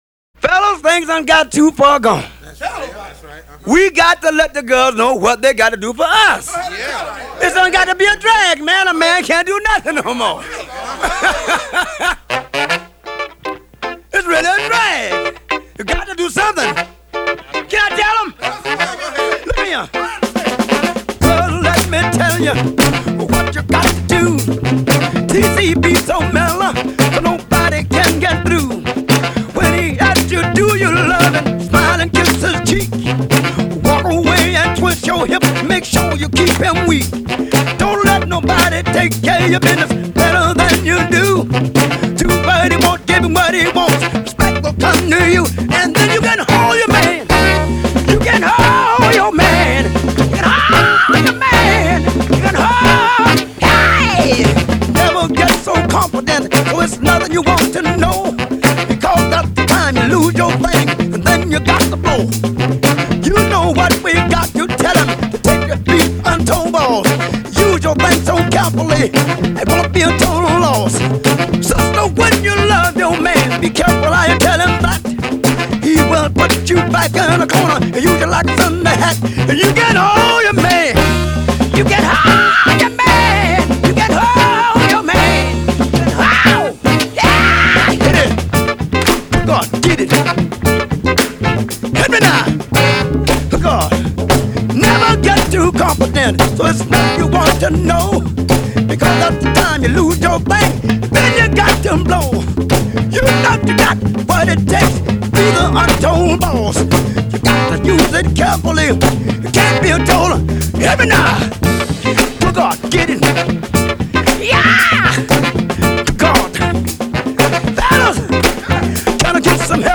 Funk, Soul